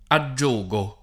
aggiogo [ a JJ1g o ], ‑ghi